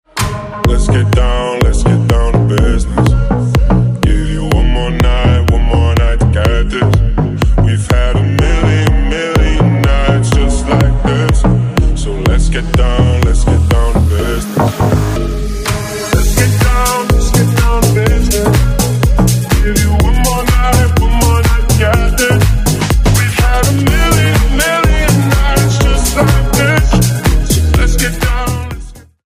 Categorie: Dance / Electronica